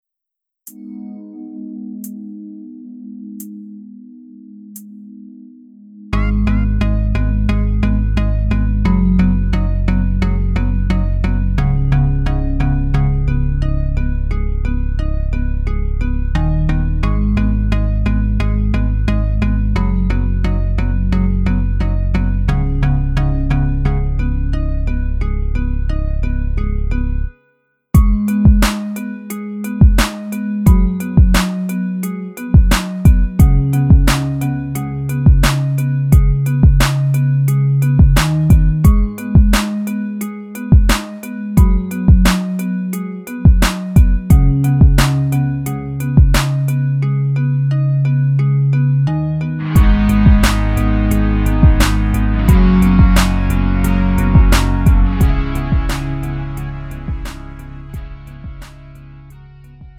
음정 -1키 4:32
장르 구분 Lite MR